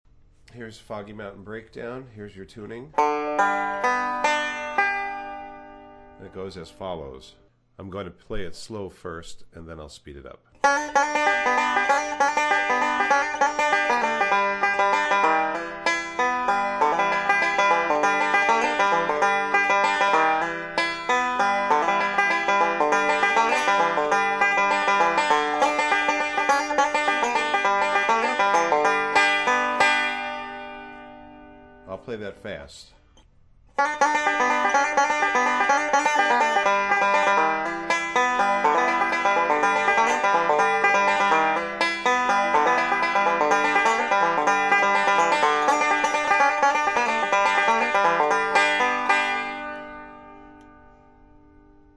banjo only, played slow,  then fast .mp3